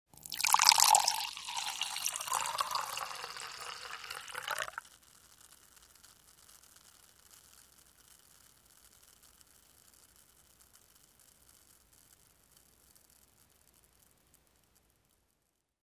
Звуки ресторана
наливают вино в хрустальный бокал